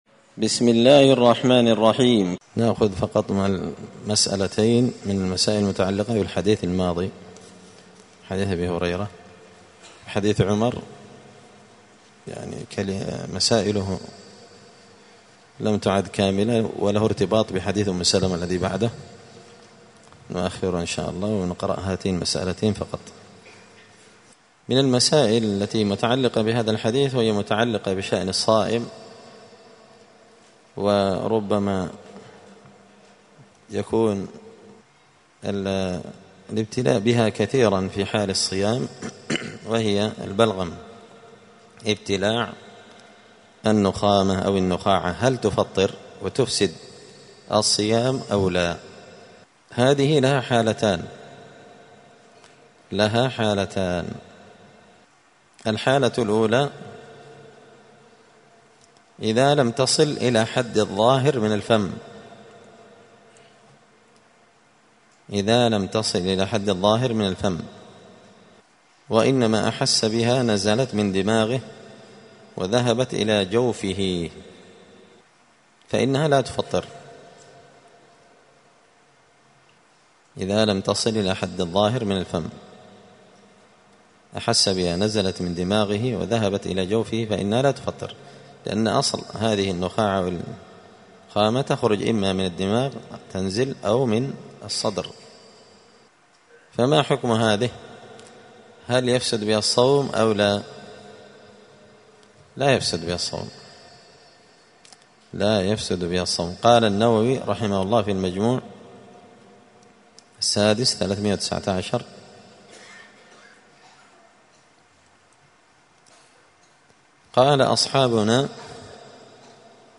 دار الحديث السلفية بمسجد الفرقان بقشن المهرة اليمن
*الدرس الحادي عشر (11) {حكم ابتلاع النخامة للصائم…}*